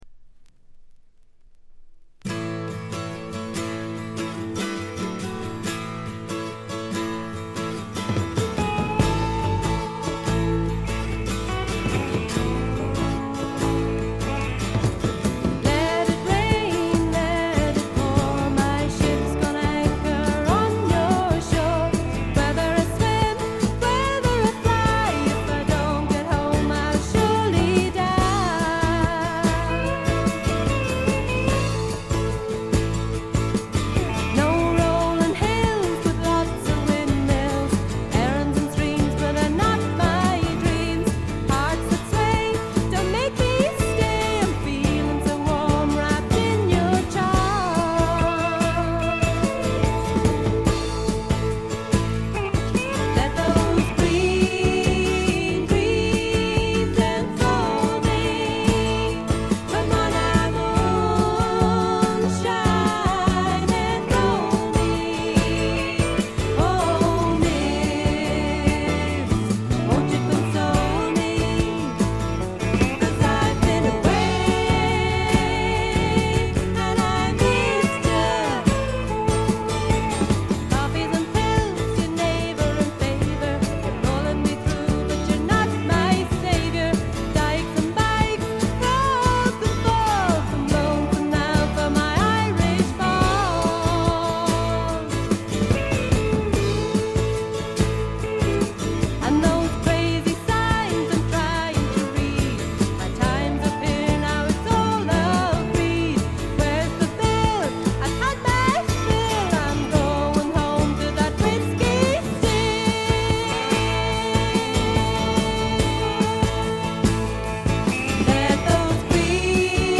ほとんどノイズ感無し。
ひとことで言って上品で風格のあるフォーク･ロックです。
試聴曲は現品からの取り込み音源です。
Recorded At Marquee Studios